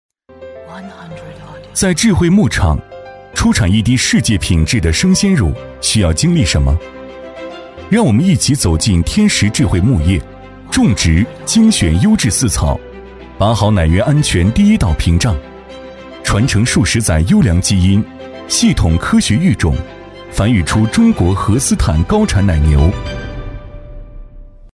男121-广告配音《嘉立荷牛奶》-大气品质
男121-广告配音《嘉立荷牛奶》-大气品质.mp3